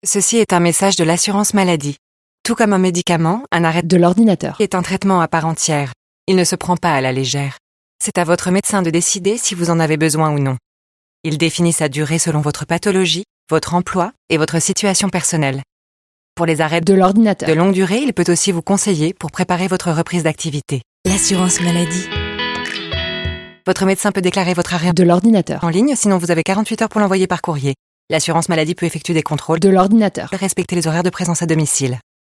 [Cette fausse publicité reprend quasi intégralement une publicité radio de l'Assurance maladie de 2017, à la différence que de l'ordinateur a été inséré à la place de du travail]